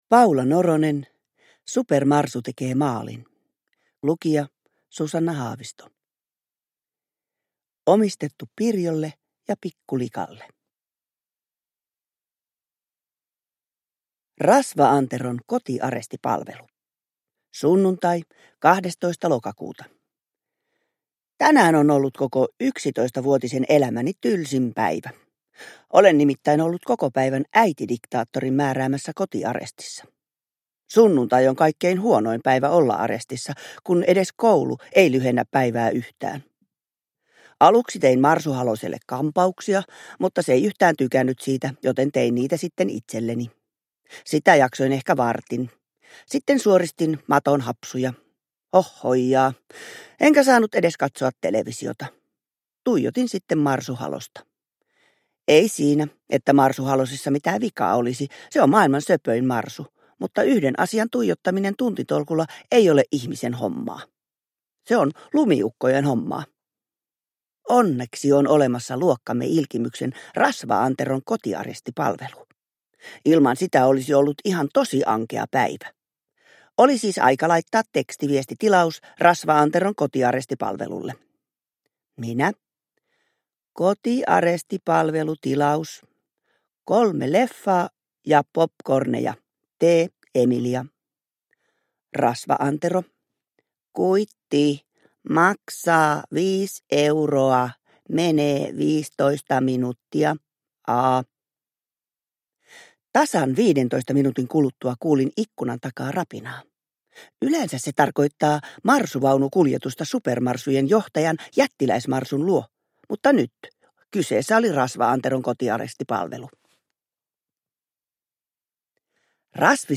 Supermarsu tekee maalin – Ljudbok – Laddas ner
Uppläsare: Susanna Haavisto